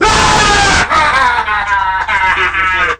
Worms speechbanks
Ohdear.wav